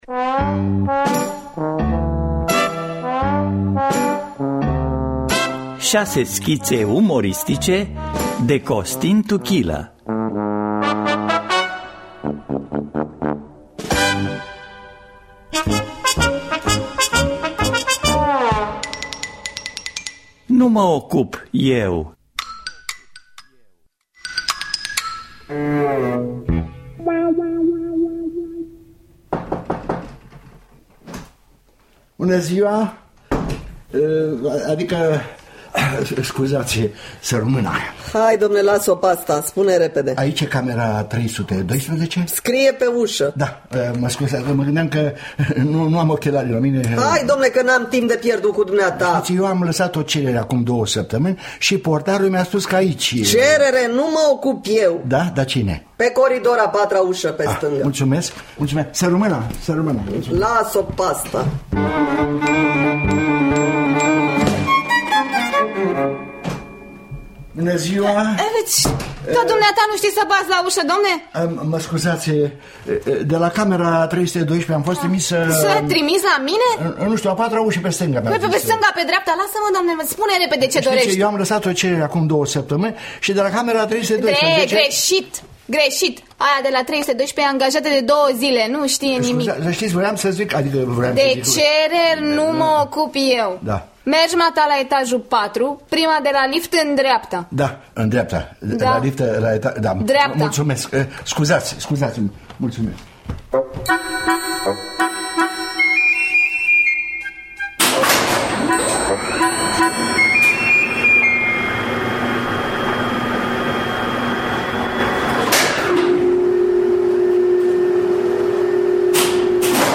Șase schițe umoristice de Costin Tuchilă – Teatru Radiofonic Online
Șase schițe umoristice de Costin Tuchilă